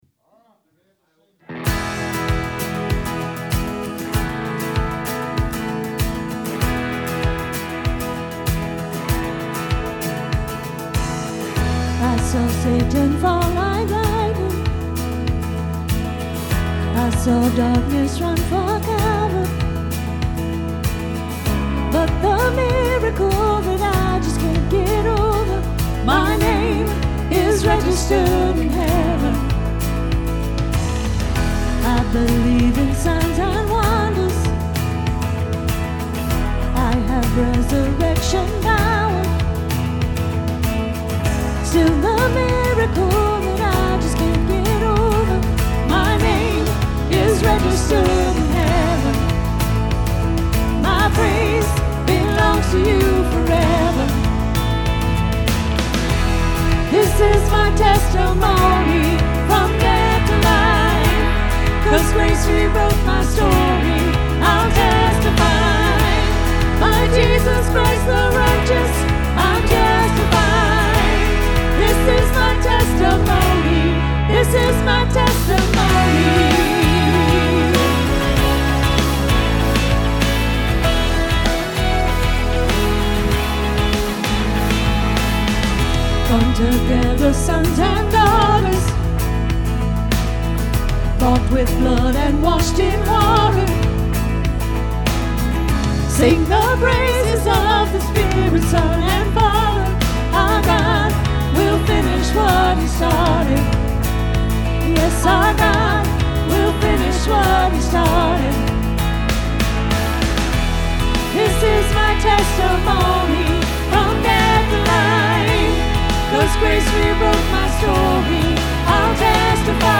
Praise Team Audio